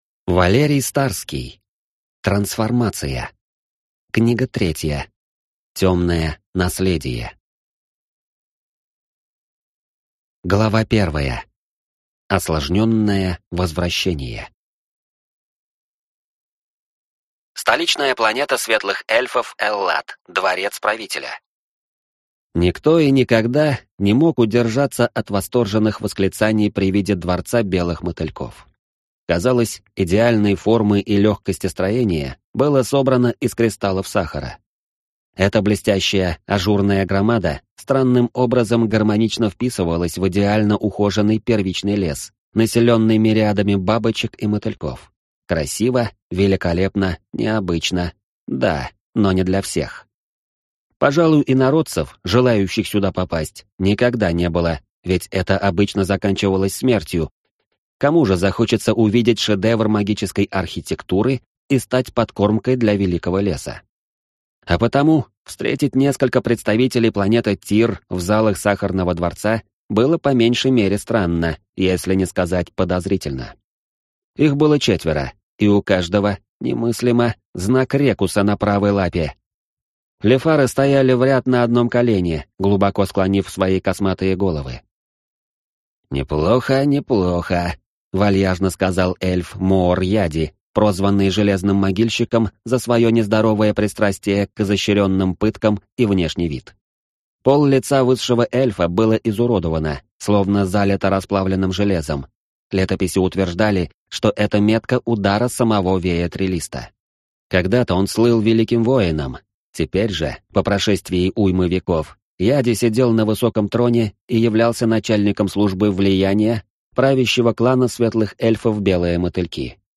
Аудиокнига Темное Наследие | Библиотека аудиокниг
Прослушать и бесплатно скачать фрагмент аудиокниги